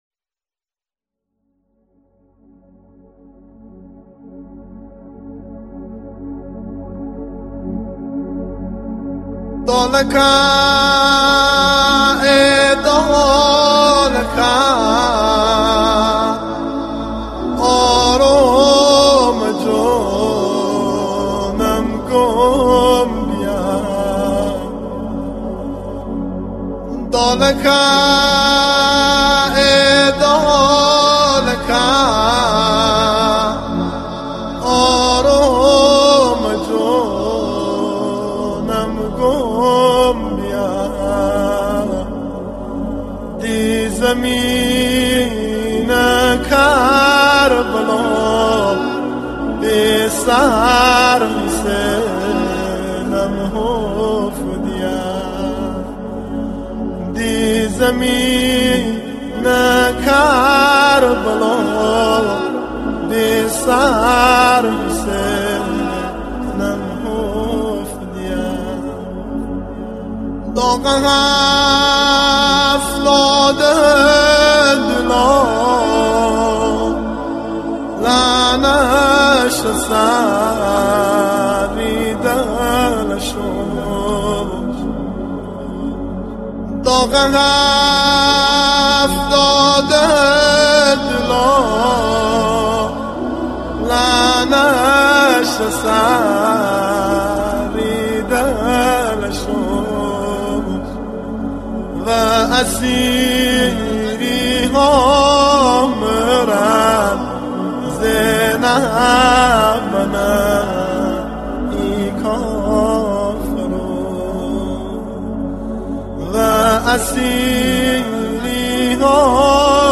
مداحی و نوحه لری